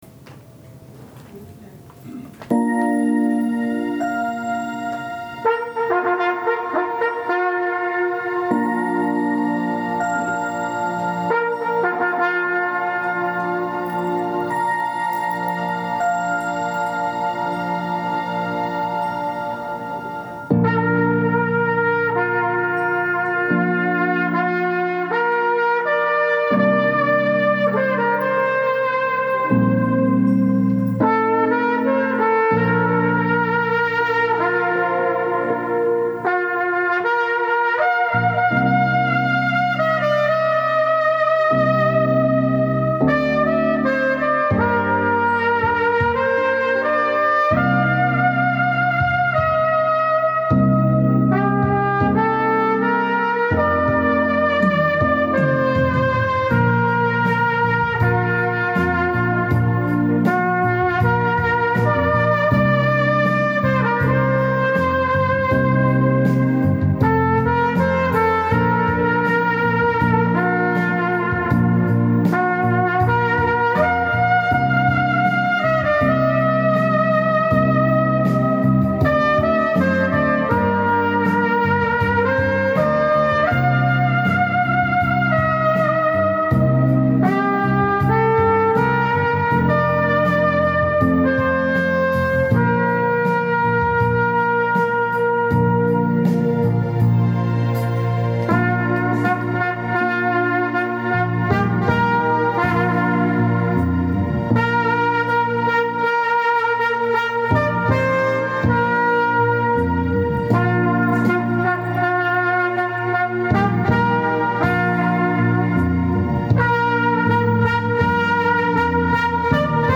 久しぶりにポータブルレコーダーで録音してみたのでそれをアップします。曲は「夢のトランペット」です。
夢のトランペット（実況録音）
※録音を後で聞いて、最後に「いいねえ～」という一言が入っているのに気がつきました。
良いですね、温かい演奏に心が優しくなります。